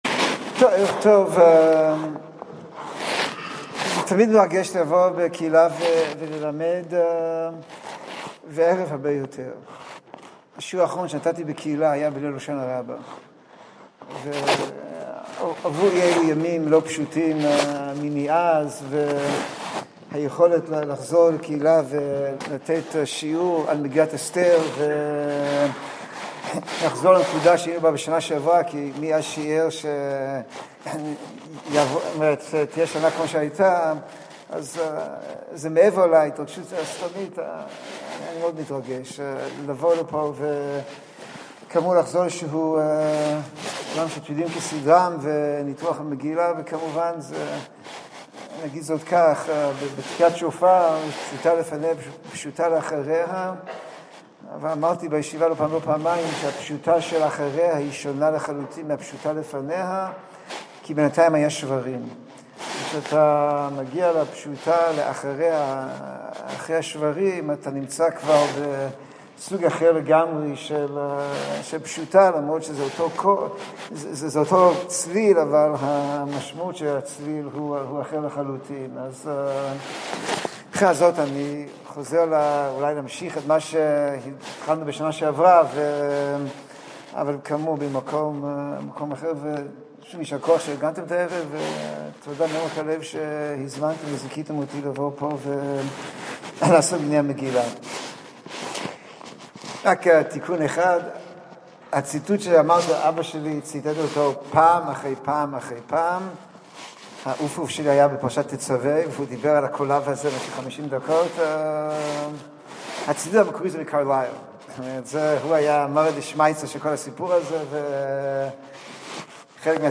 בין תמרוקים ובגדים במגילת אסתר. השיעור הועבר בבית כנסת נריה פתח תקוה כז אדר א תשפ"ד במסגרת "קרוב אליך"